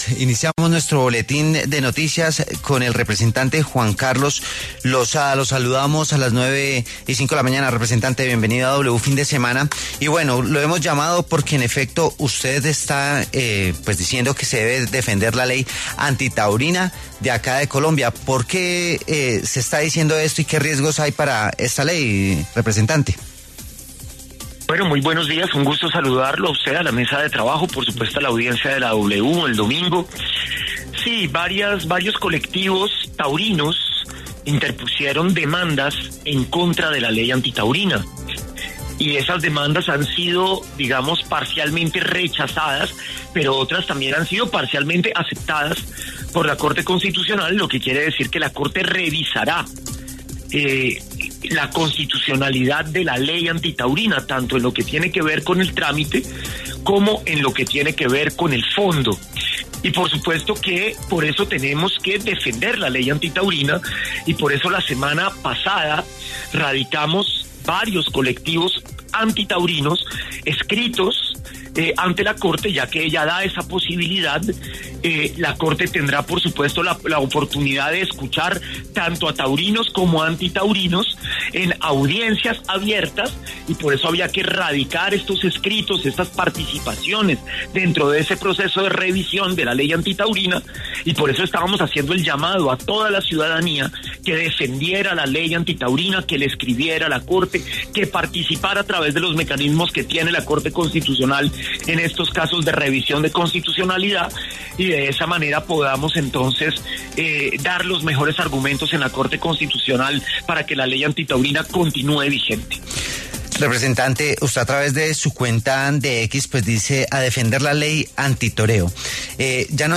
En diálogo con W Fin de Semana, el representante a la Cámara Juan Carlos Losada se refirió a las demandas que han interpuesto colectivos taurinos contra la ley que prohíbe las corridas de toros en Colombia.